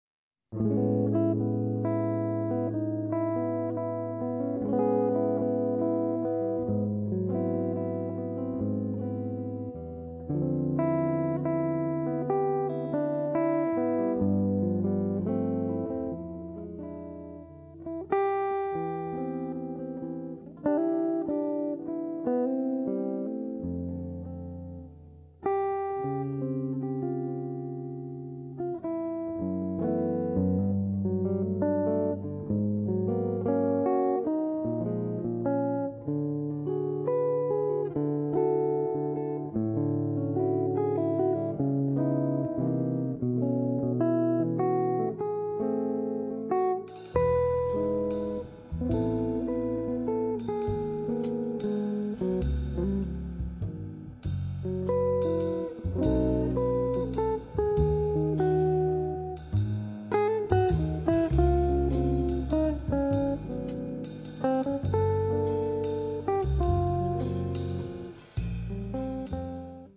Chitarra
Sax Baritono
Contrabbasso e Basso Elettrico
Batteria
bel valzer zuccherino